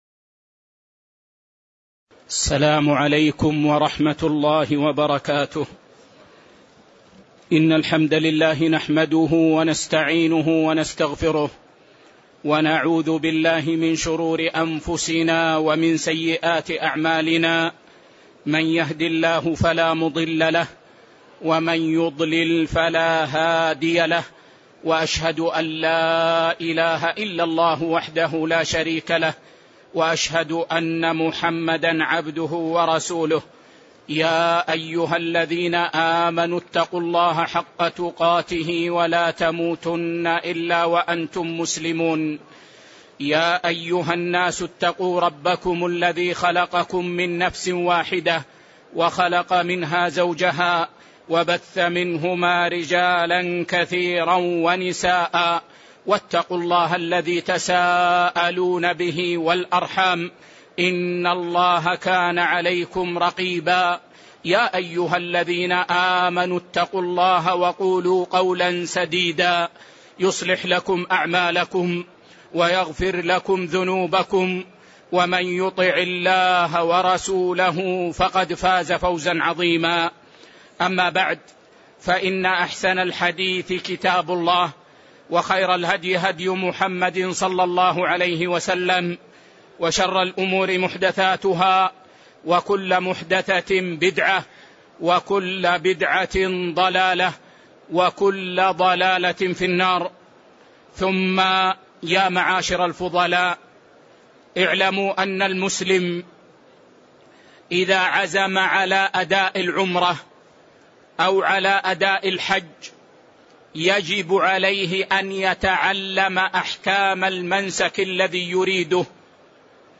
تاريخ النشر ٢٦ ذو القعدة ١٤٣٩ هـ المكان: المسجد النبوي الشيخ